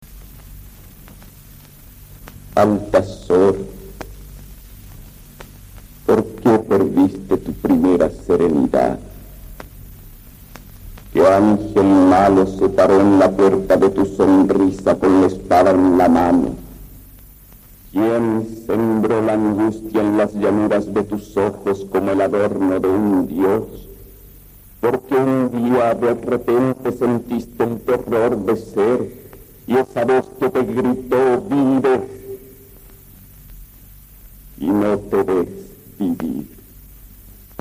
Cassette sonoro